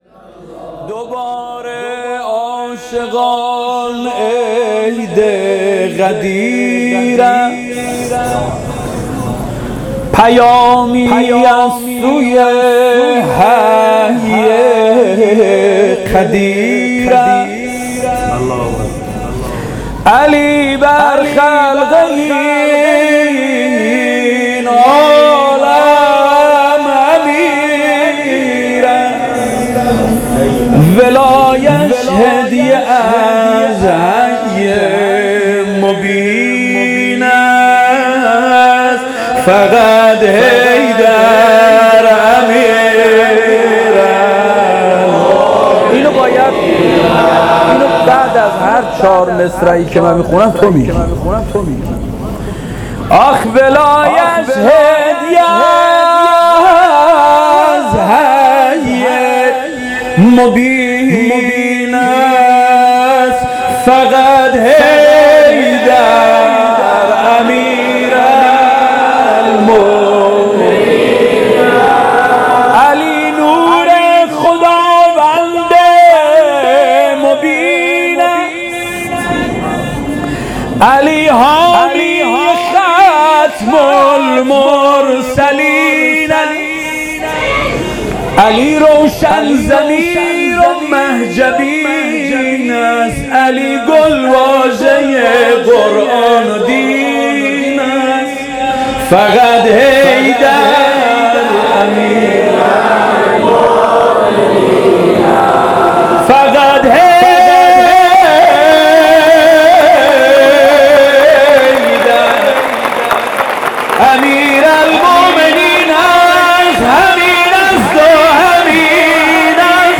عید غدیر ۹۸